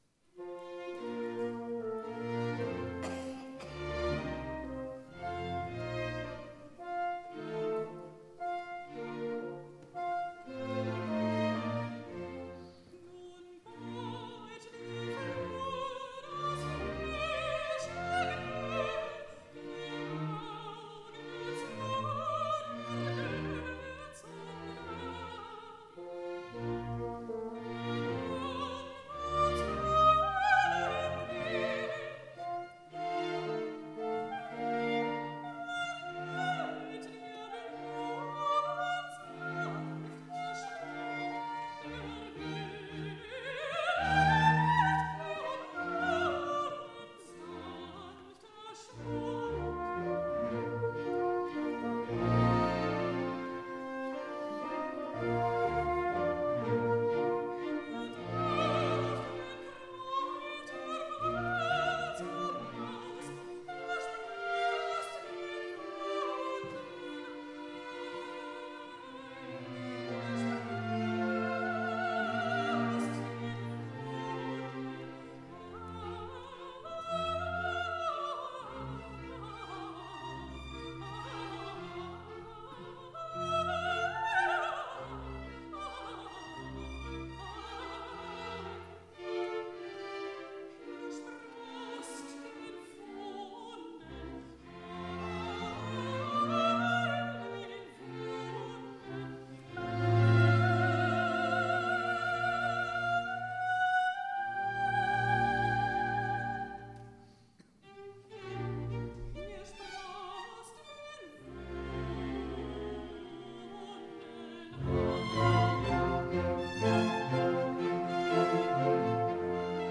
Sopran
J. Haydn: Nun beut die Flur das frische Grün, Schöpfung (Live-Mitschnitt)